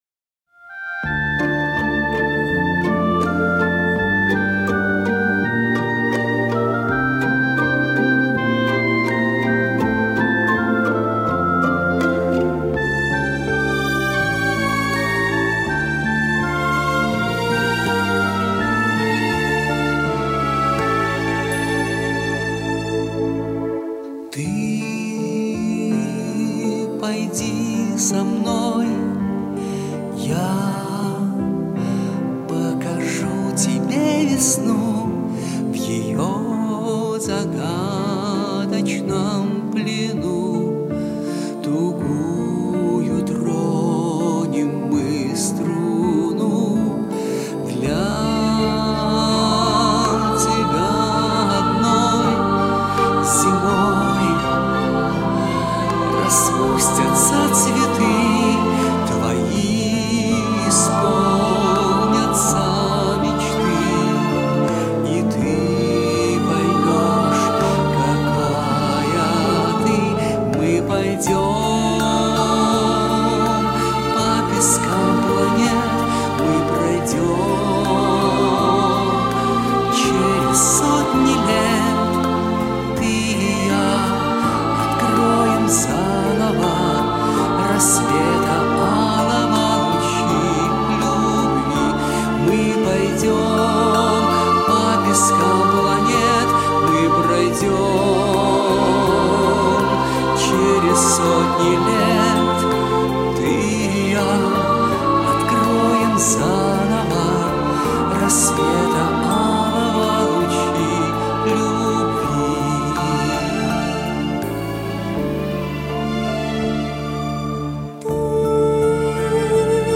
Еще один дуэт